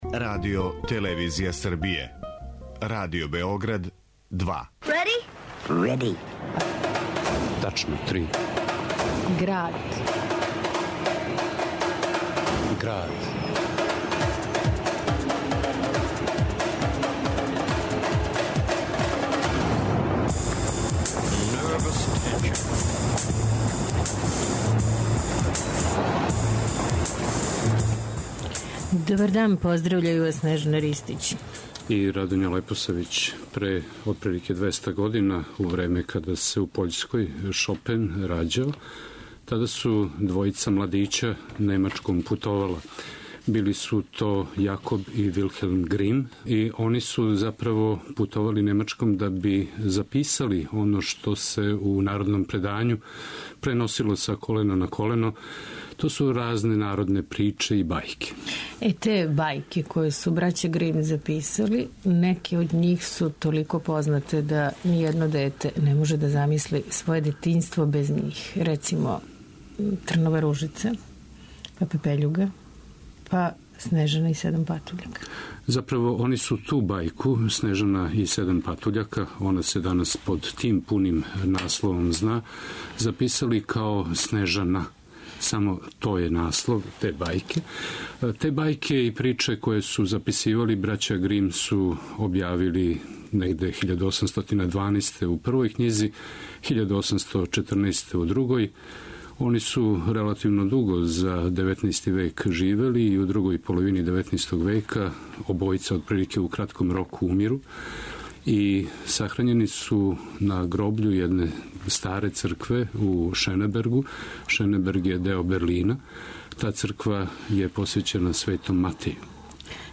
Уз додатне коментаре.